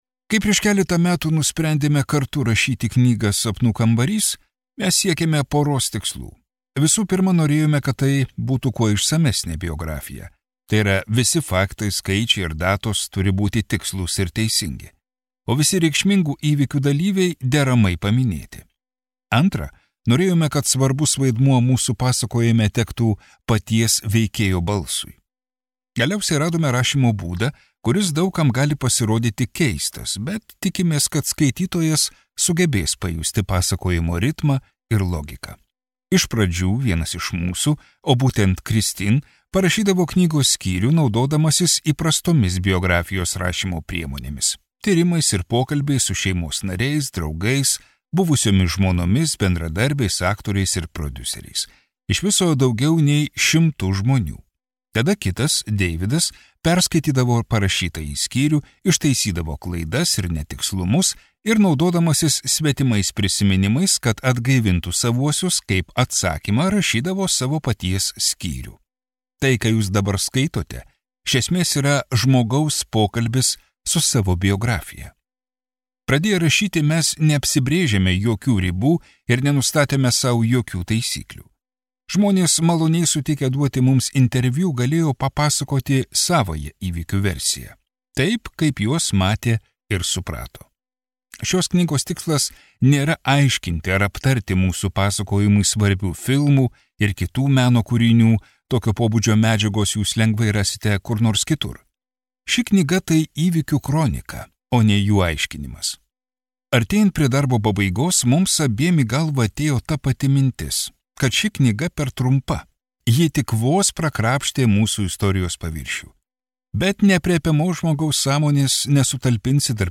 Sapnų kambarys | Audioknygos | baltos lankos leidykla